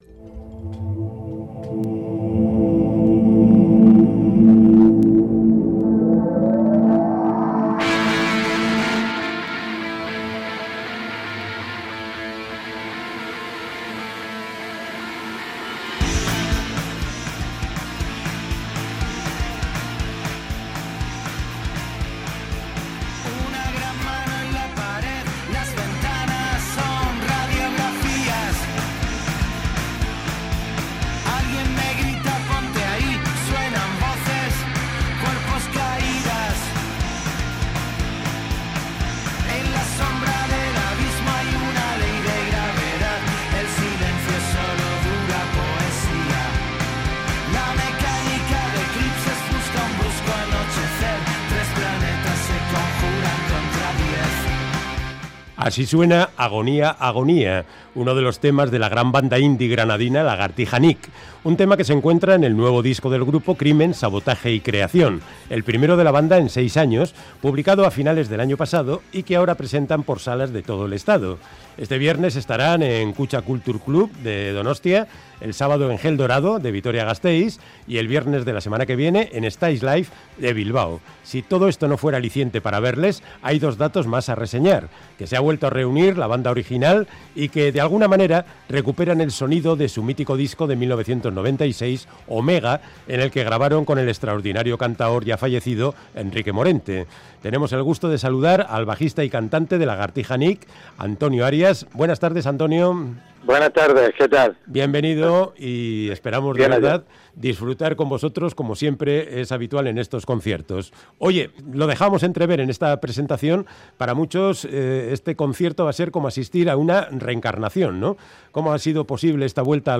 Audio: Charlamos con Antonio Arias, líder de la banda indie granadina Lagartija Nick, que este viernes inicia una minigira por Euskadi con su nuevo disco Crimen, sabotaje y creación, un retorno a Omega